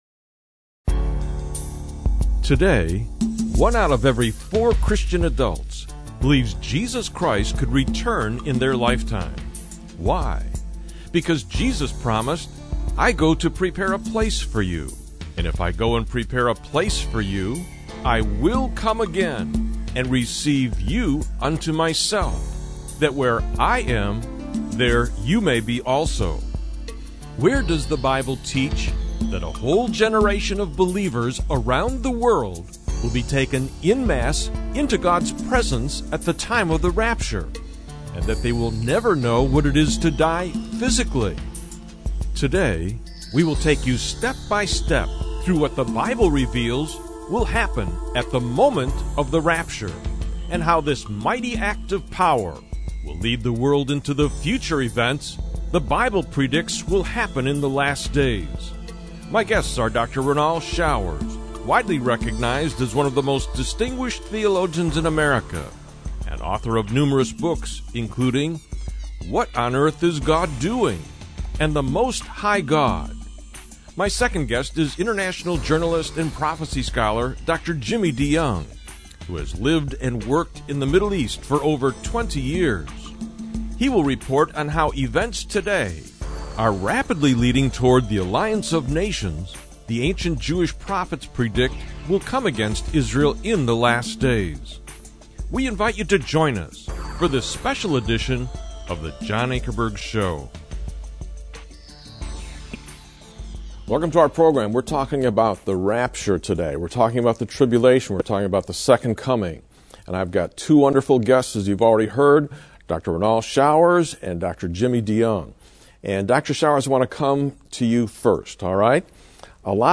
The formats of choice for this apologetics ministry are informal debates between representatives of differing belief systems, and documentary-styled presentations on major issues in society to which the historic Christian faith has something of consequence to say.
Talk Show